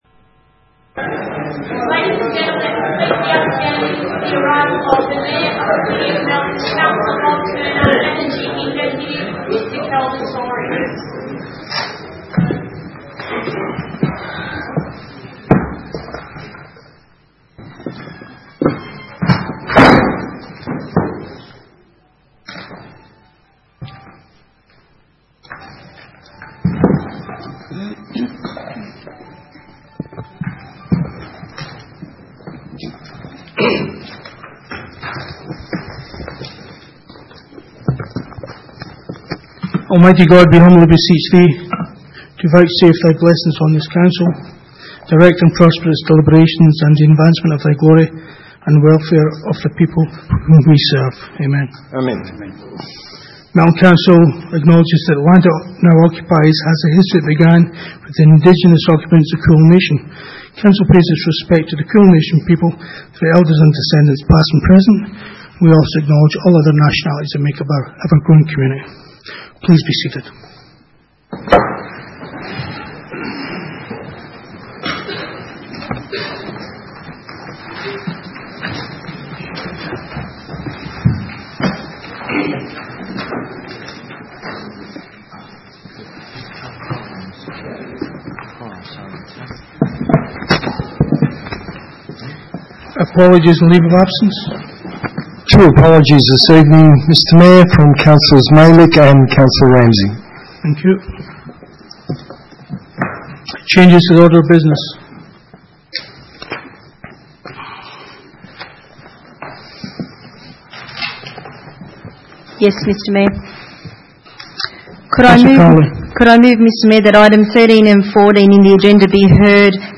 Ordinary meeting 15 October 2018